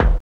GATED GRIT.wav